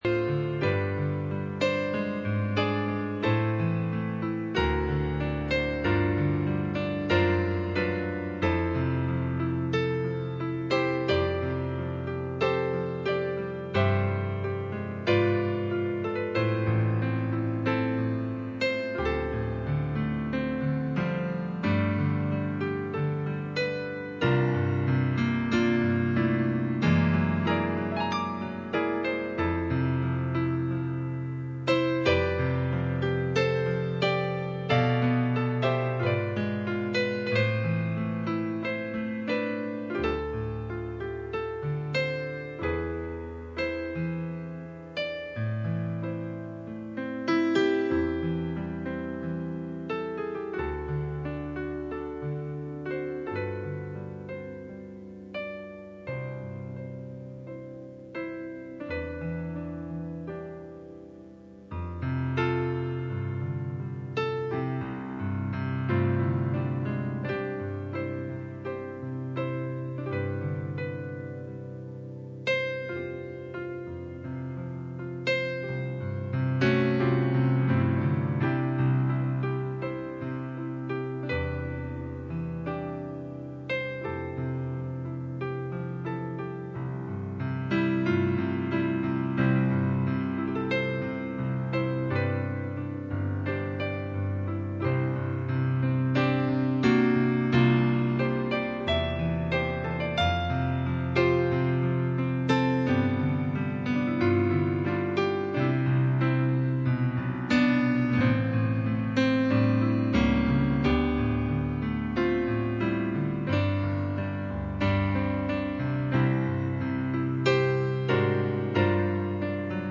Pastor Passage: Revelation 17:14 Service Type: Midweek Meeting %todo_render% Church Ages 64